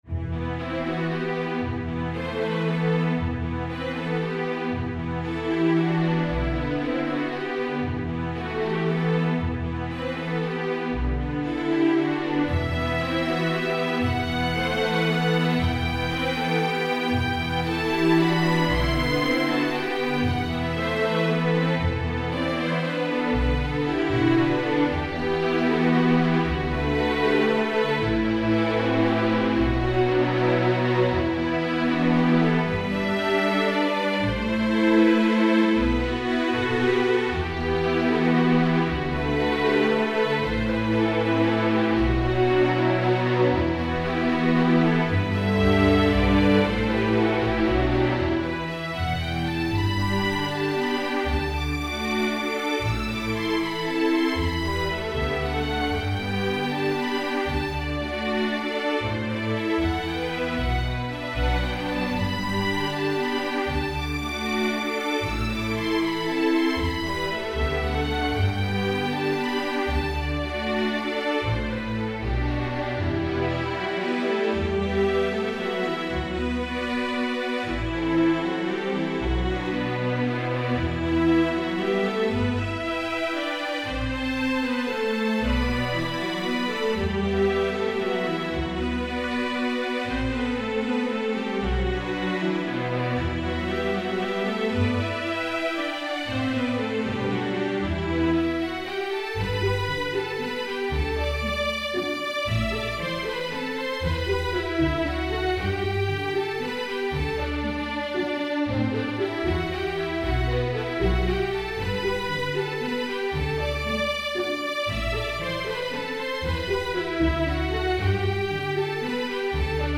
ADVANCED, STRING QUARTET
Notes: straight eighth notes
1st Violin 3rd and 4th positions
Key: E minor